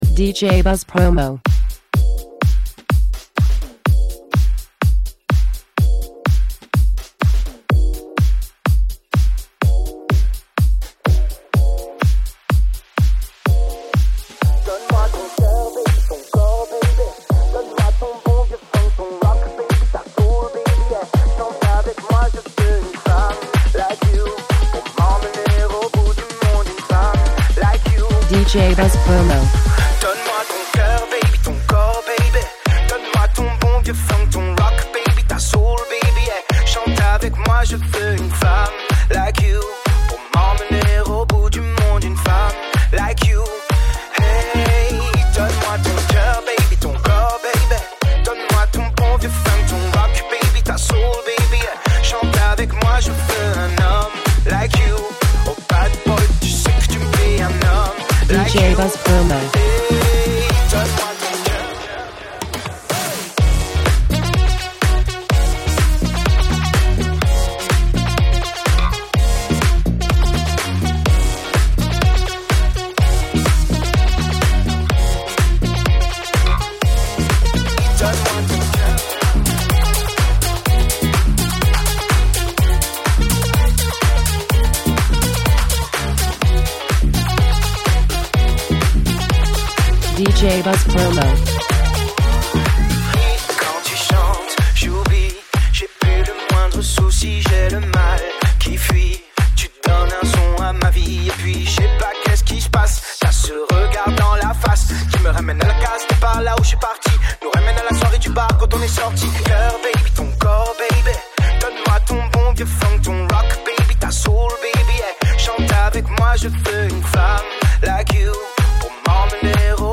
Super remake!